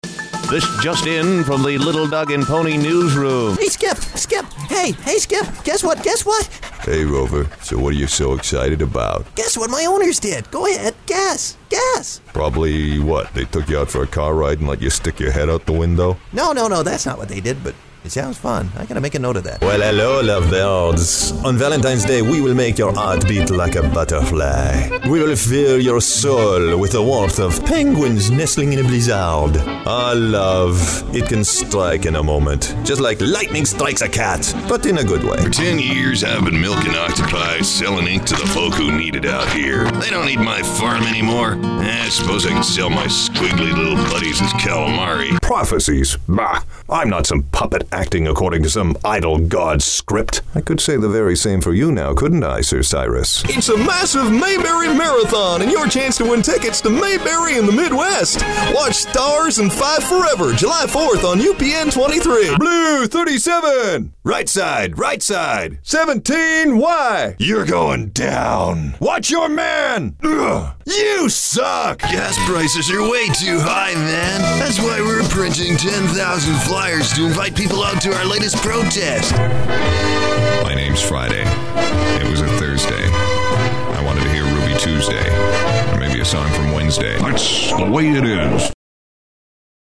Character Voice Overs / Character Voiceover Actor Demos
Male voice over talent